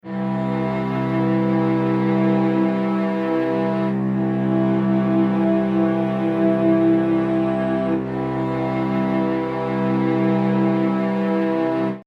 This may be why the equal-tempered major third gives me that slight queasy feeling.
Here it is again: pure third, ET third, pure third. The middle note, the ET third, has a ratio of about 5.04/4.
Is it slight tonal vertigo? Where is home?
JI3-vs-ET3.mp3